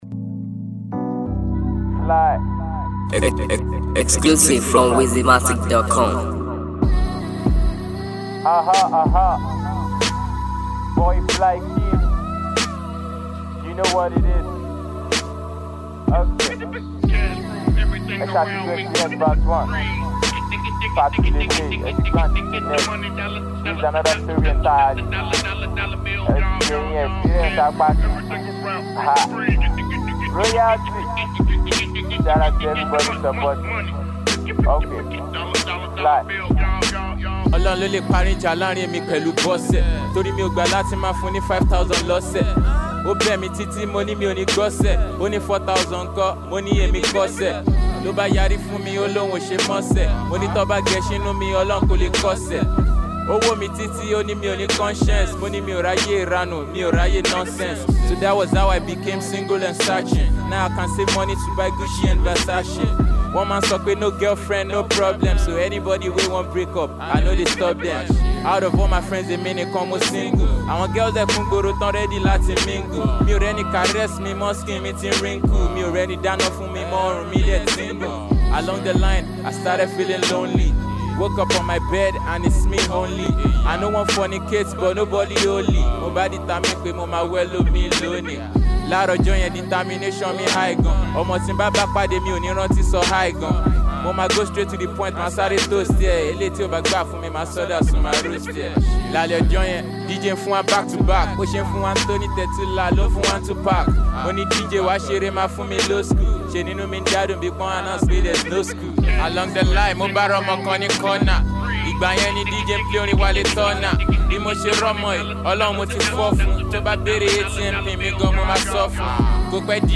rap freestyle